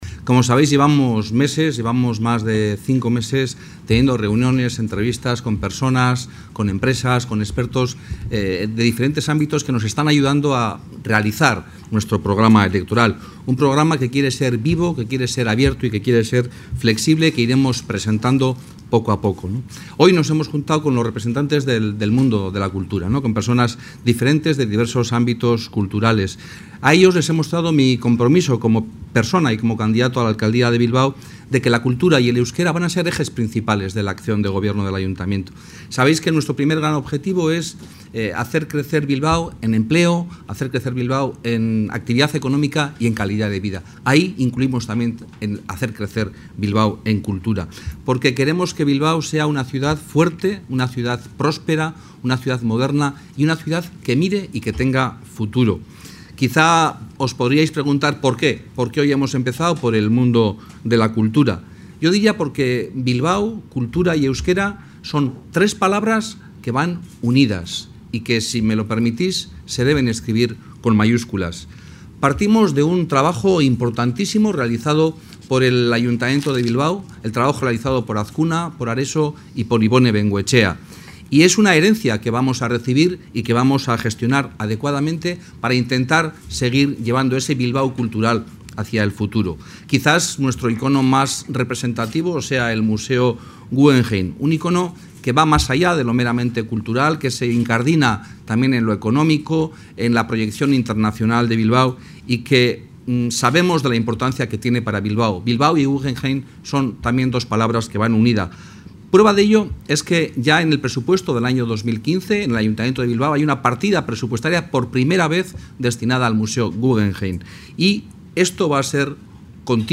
• Juan Mari Aburto en el encuentro sectorial sobre cultura 22/04/2015